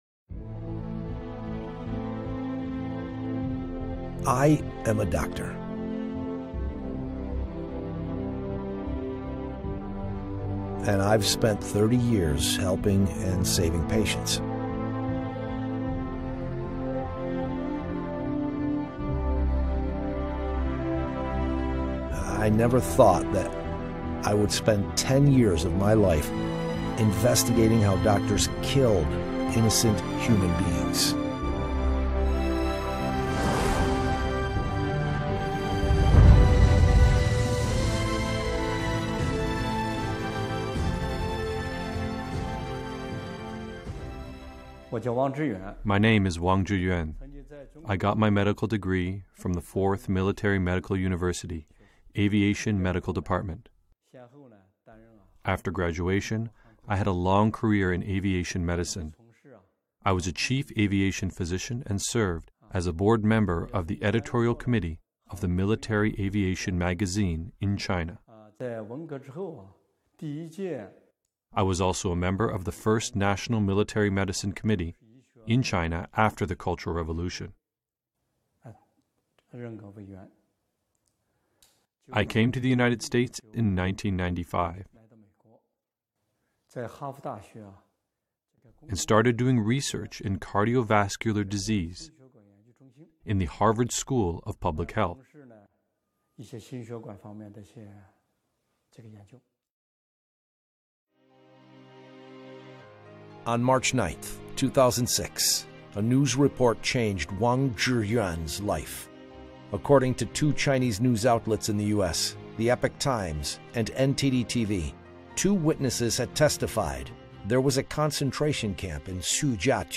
Harvested Alive - Forced Organ Harvesting - Documentary